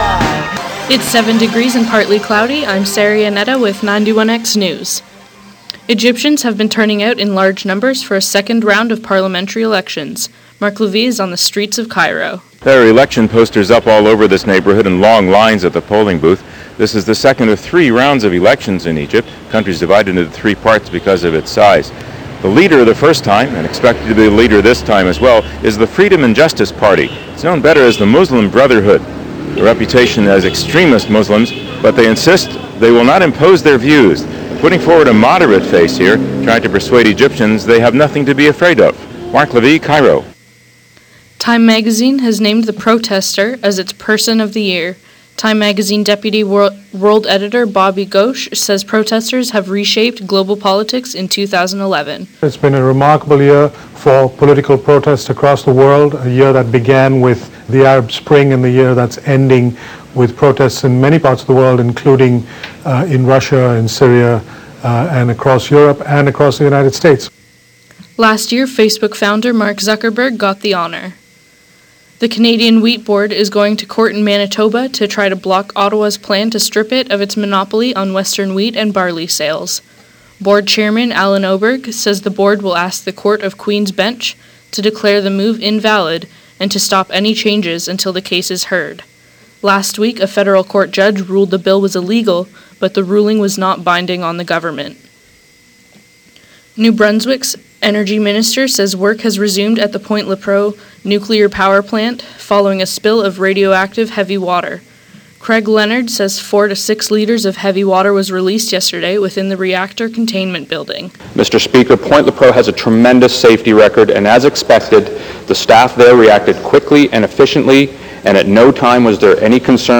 For these stories, sports and more listen to the 2 p.m. newscast.